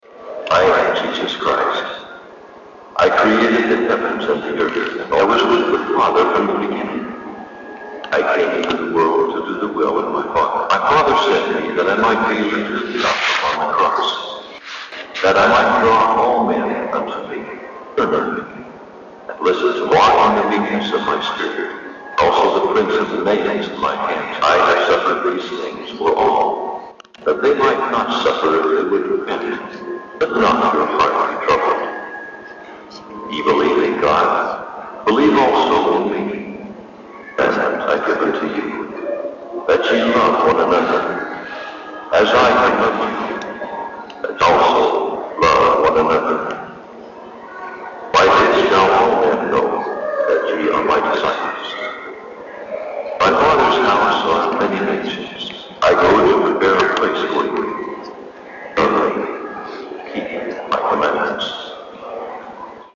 Abbildung [4]: Im Besucherzentrum des Mormonentempels
Stimme von Jesus Christus vorgespielt bekamen.